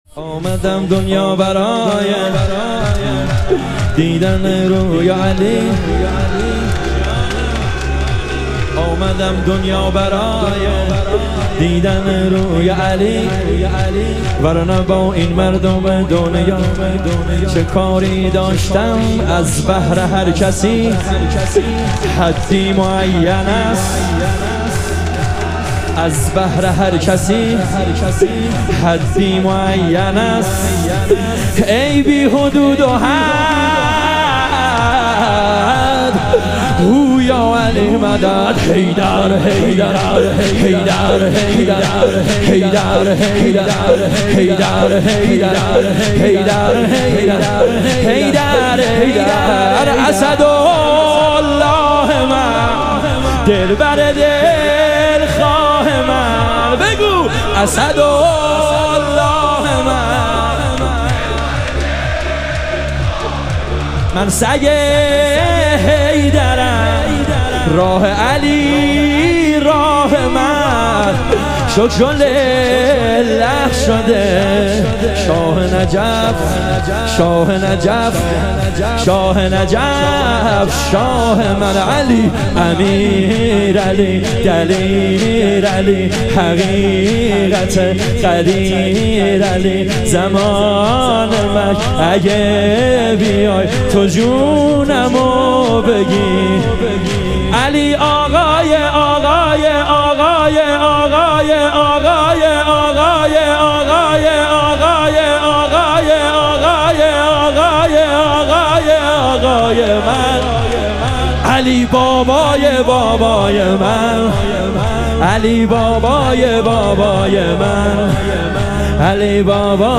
ایام فاطمیه اول - شور